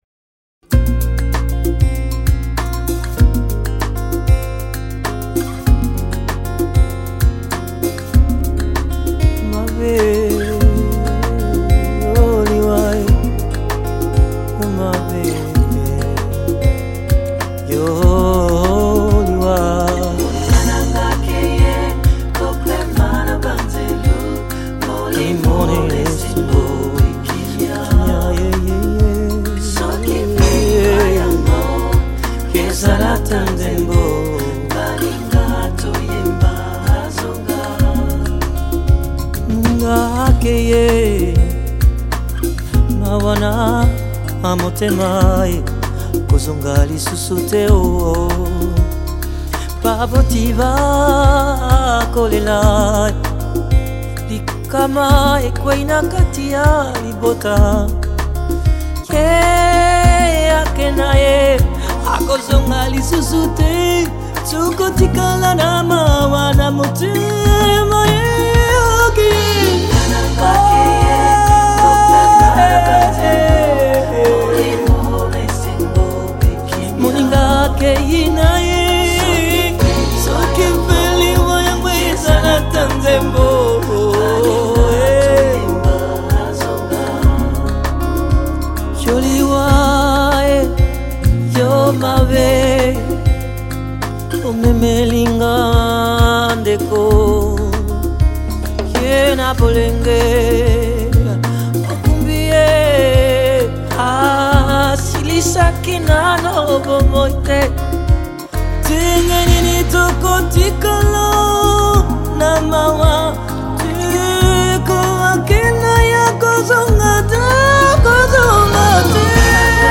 Genre: African